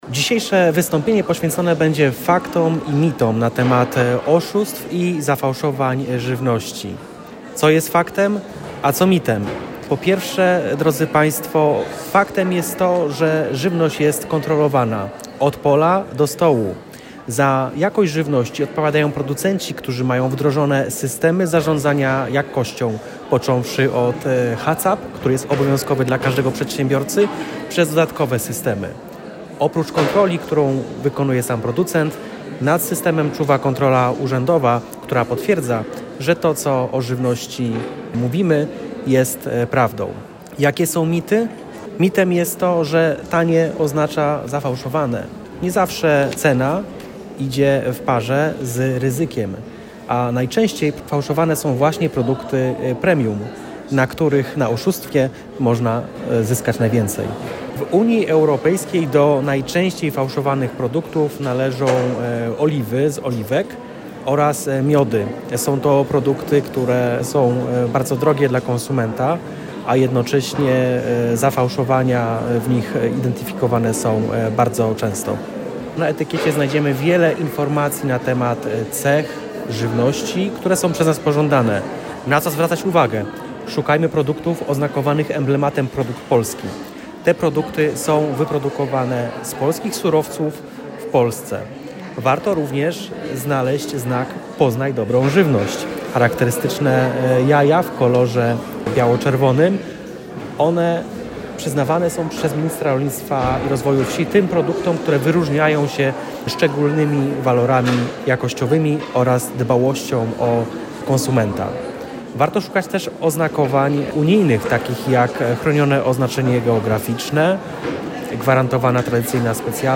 – mówił dr Rzodkiewicz.